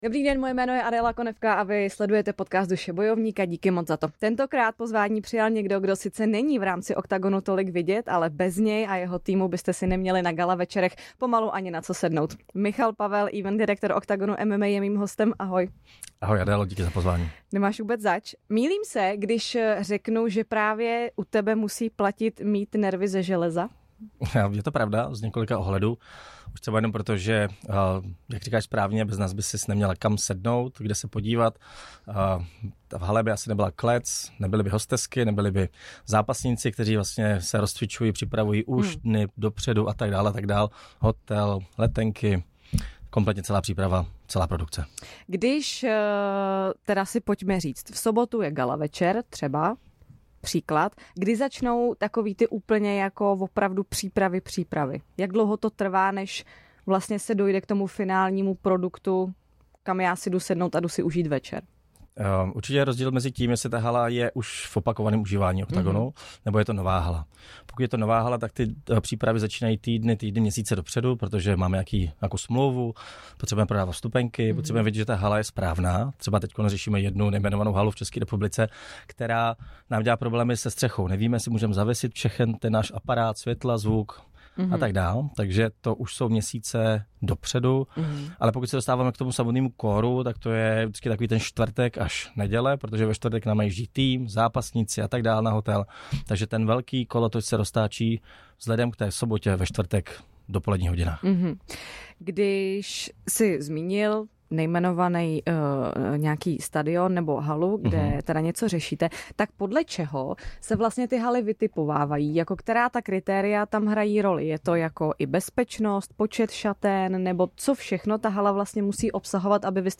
Jaké jsou největší výzvy tohoto sportovního „showmanství“? A co vše stojí za perfektním večerem Oktagonu? Dozvíte se v nejnovějším rozhovoru